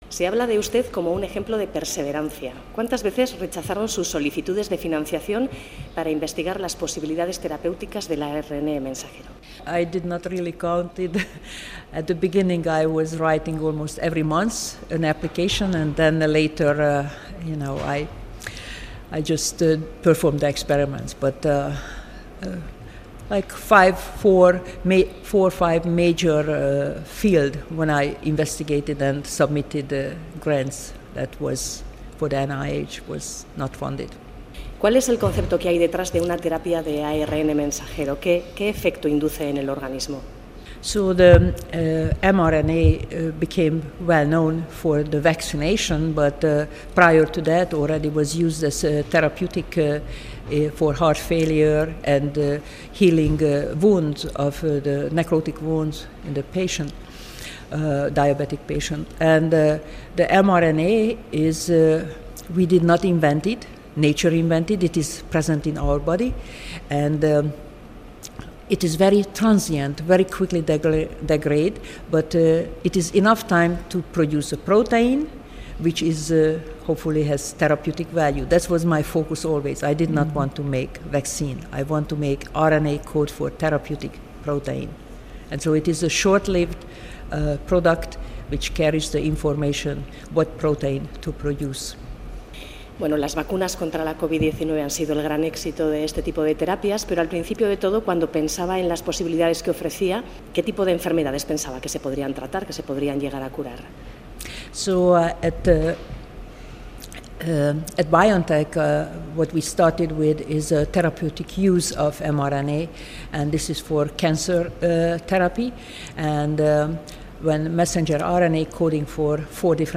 Katalin Karikó, la bioquímica perseverante - entrevista en inglés